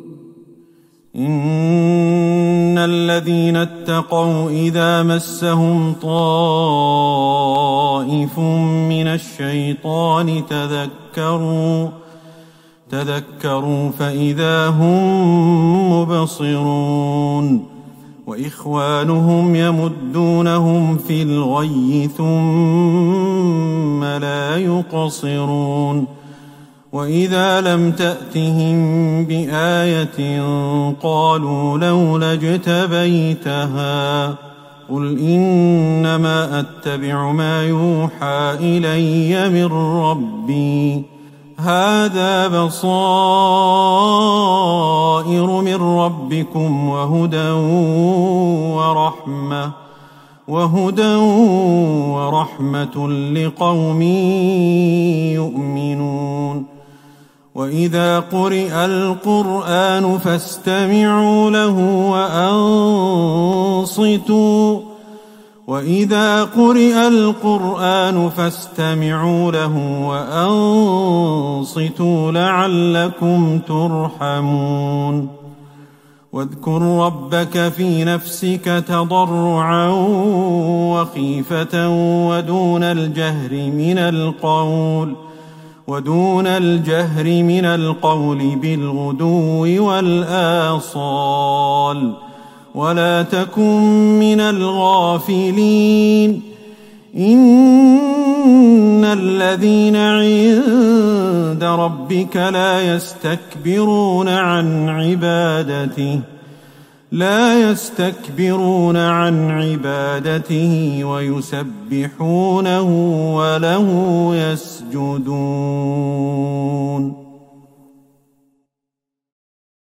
ليلة ١٢ رمضان ١٤٤١هـ من سورة الأعراف { ٢٠١-٢٠٦ } والأنفال { ١-٤٠ } > تراويح الحرم النبوي عام 1441 🕌 > التراويح - تلاوات الحرمين